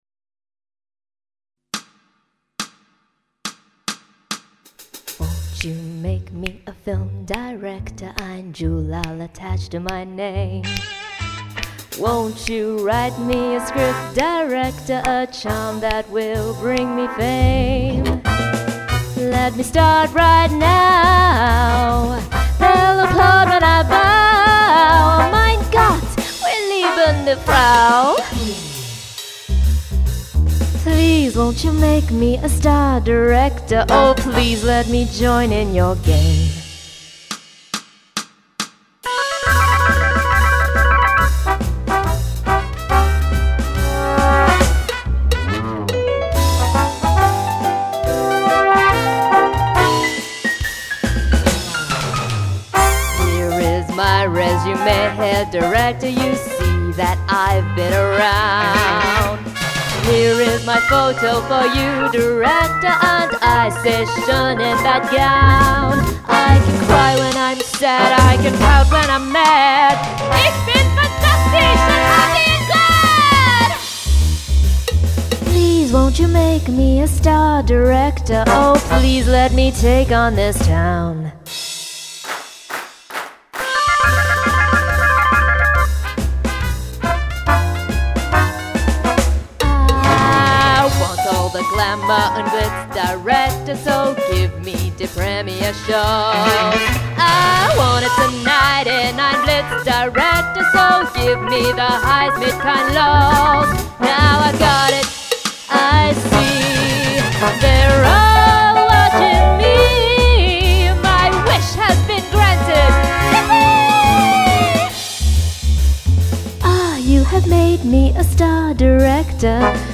ÎÊ, âîò äåìà, ÷òî ÿ äåëàëà ãîä íàçàä äëÿ ìèíèìþçèêëà: àêàåâñêèå ñýìïëû, äðàììàøèíêà BOSS, ðîÿëü÷èê è îðãàí÷èê -kurzweill, áàñ - òðèëîãèÿ.
Çà ïëîõîé ìèêñ è ïëîõî çàïèñàííûé âîêàë ÿ â îòâåòå.